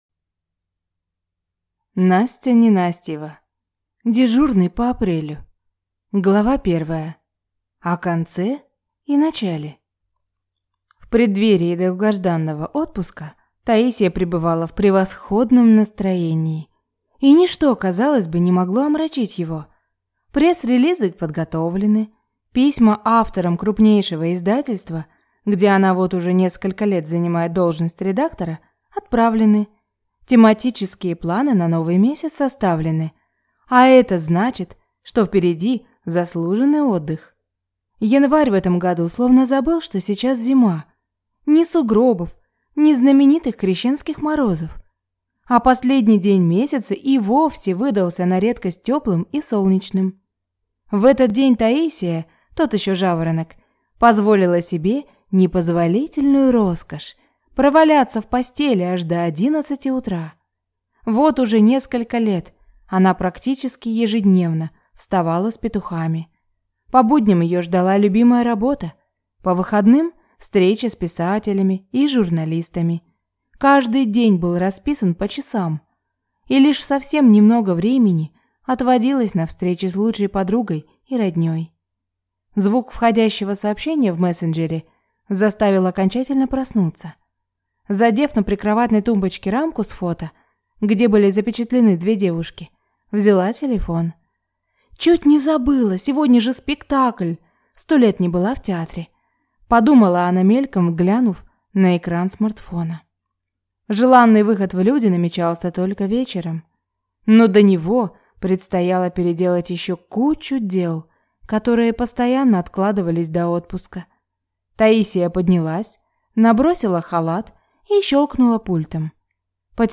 Аудиокнига Дежурный по апрелю | Библиотека аудиокниг